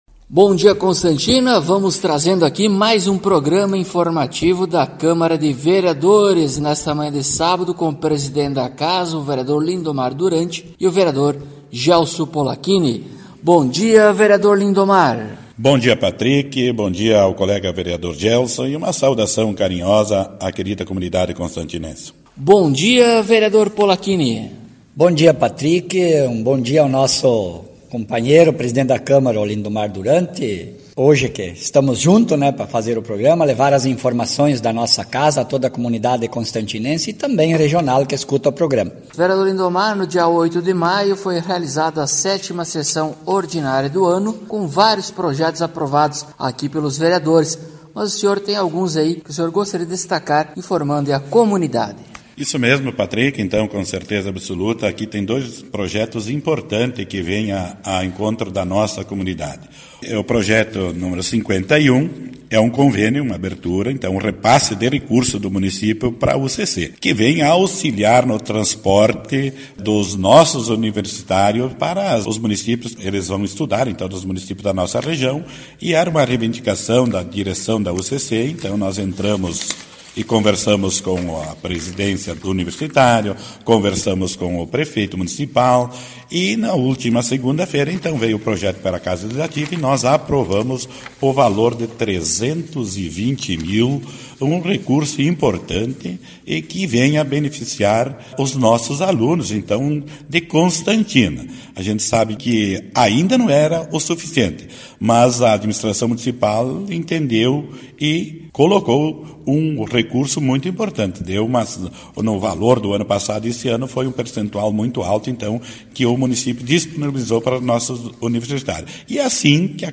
Acompanhe o programa informativo da câmara de vereadores de Constantina com o Vereador Lindomar Duranti e o Vereador Gelso Polaquini.